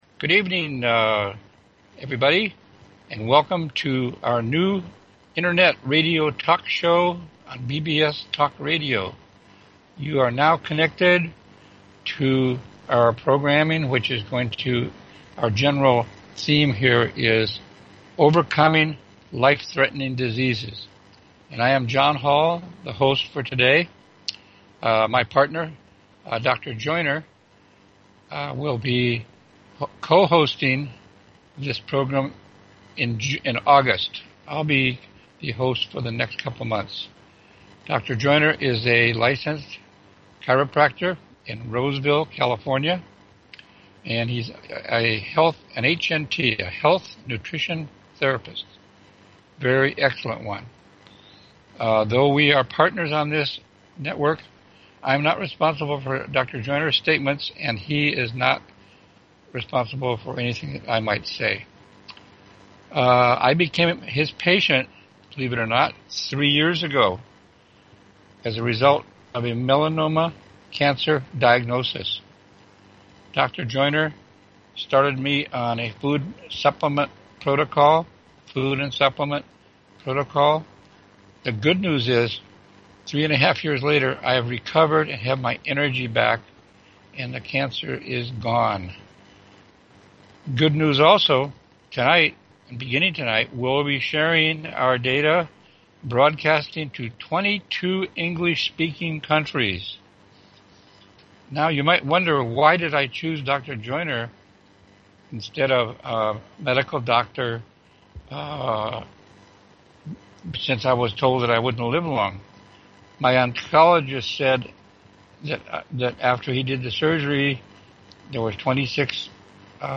Talk Show Episode, Audio Podcast, Overcoming_Life_Threatening_Diseases and Courtesy of BBS Radio on , show guests , about , categorized as